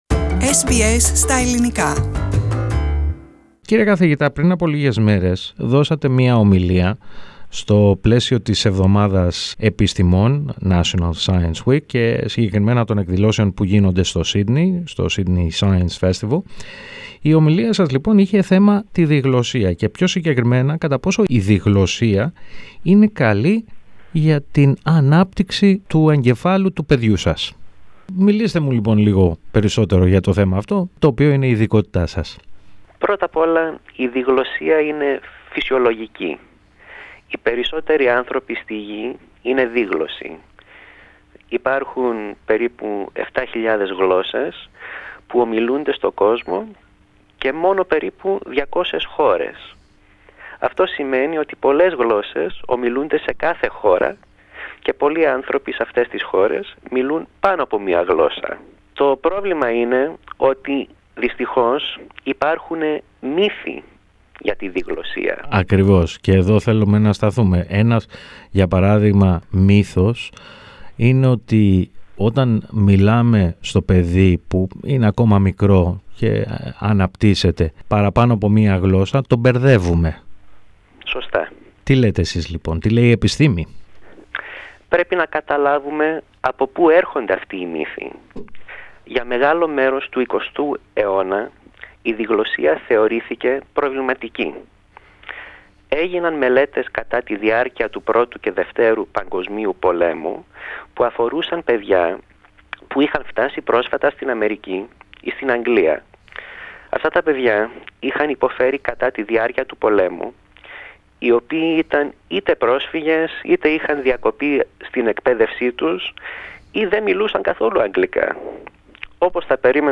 He spoke to SBS Greek.